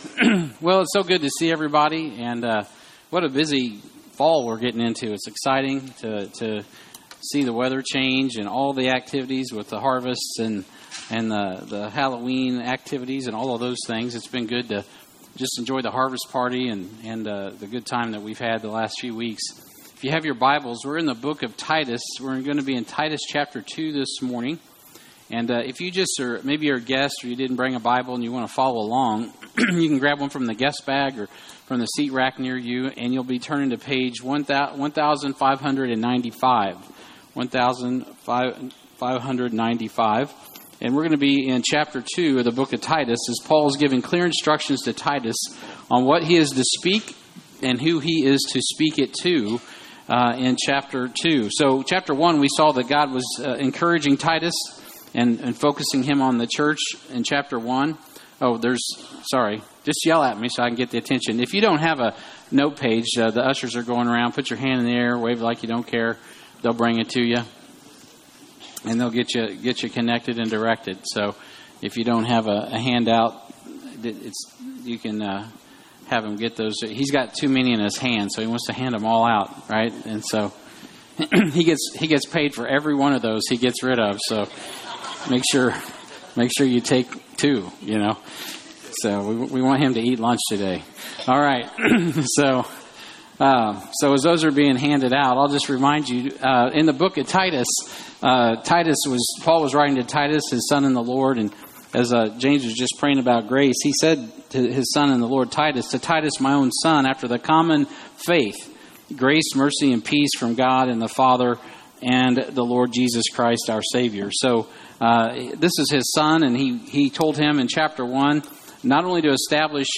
Sermons | Heartland Baptist Fellowship